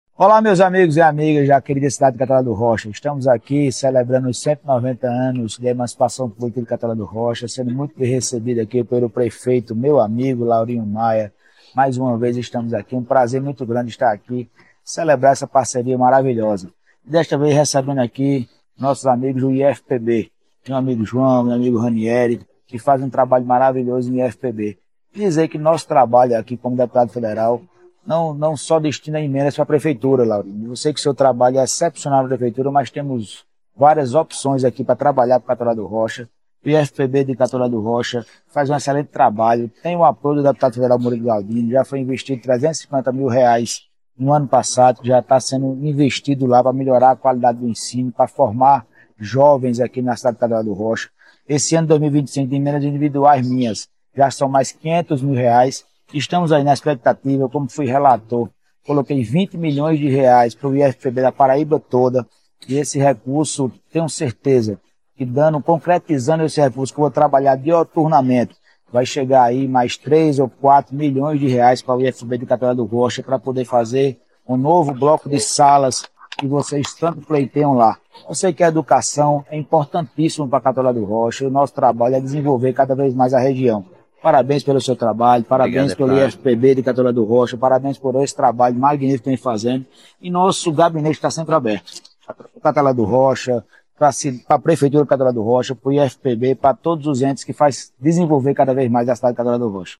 Ouça o deputado federal Murilo Galdino que fala sobre os R$ 500 mil destinados ao IFPB que irá fortalecer o futuro da juventude em nossa região.